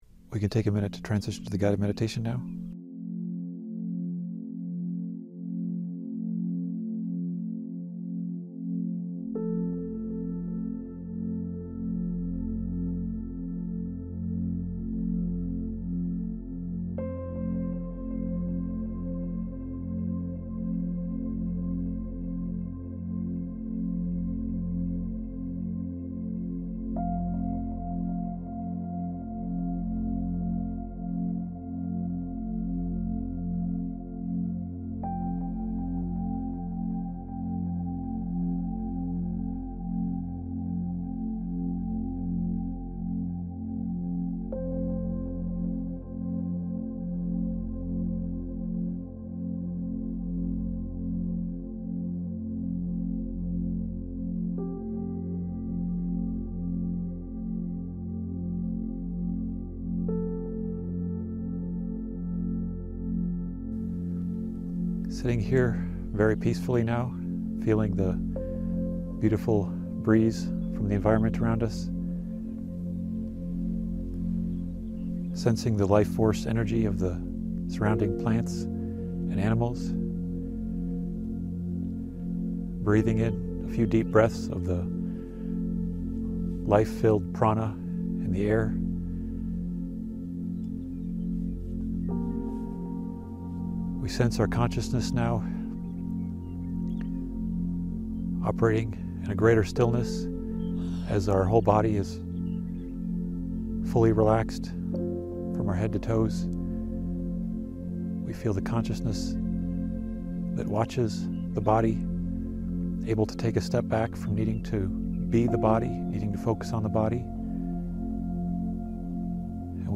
This guided meditation focuses on connecting with the earth, expanding awareness inward, and exploring unity with higher dimensions and cosmic consciousness. It encourages participants to embrace love, light, and peace, fostering healing and harmony for themselves and the planet.